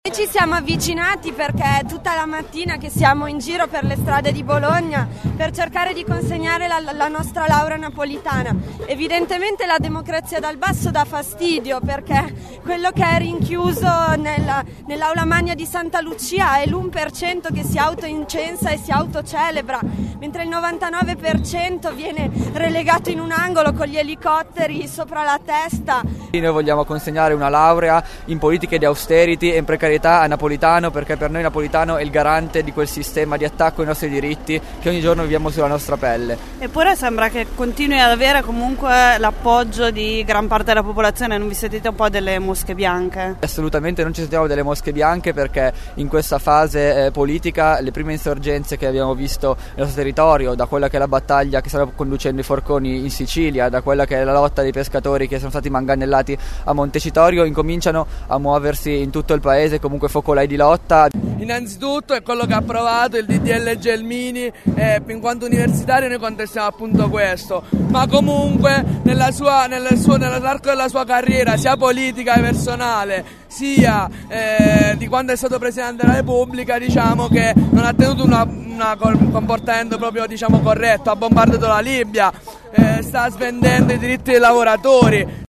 Le voci dalla piazza
voci-manifestanti-occupyunibo.mp3